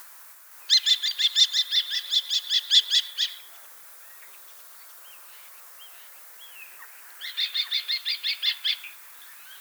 Torenvalk